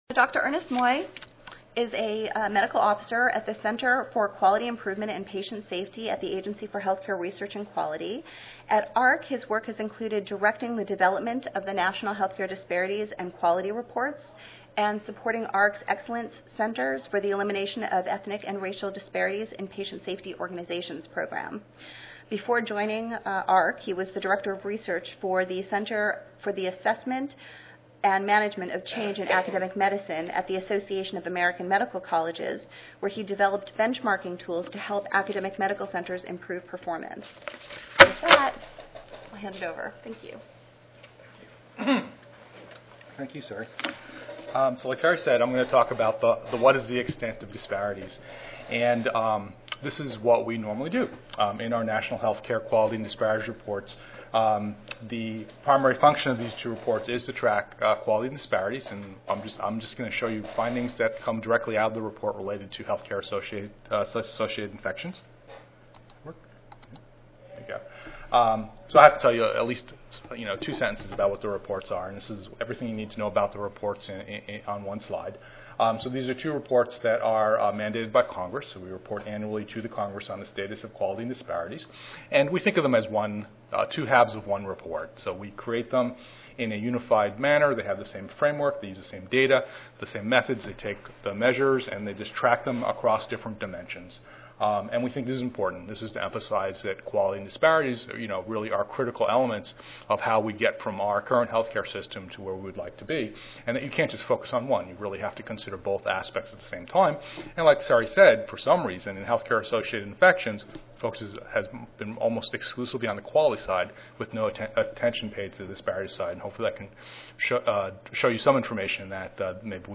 4306.0 Disparities in Hospital Acquired Infections Tuesday, November 1, 2011: 2:30 PM Oral Session Objectives: The participant will: 1. Learn about the persistence of racial and ethnic disparities in healthcare-acquired infections. 2.